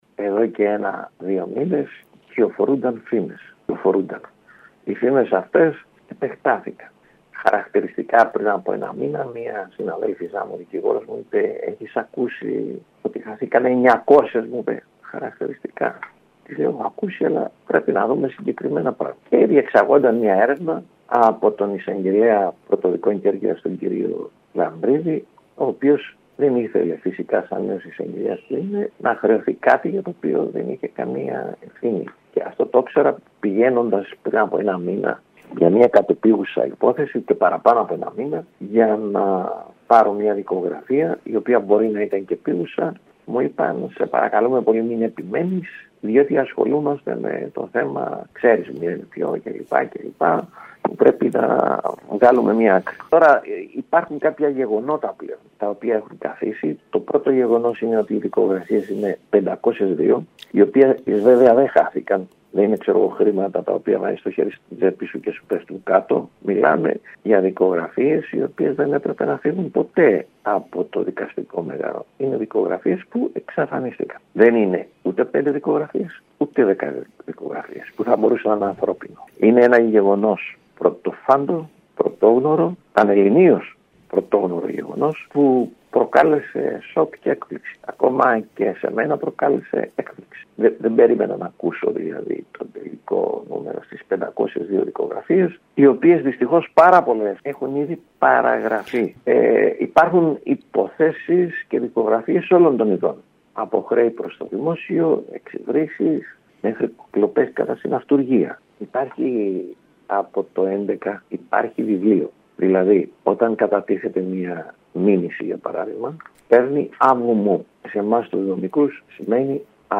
Αυτό δήλωσε στο σταθμό μας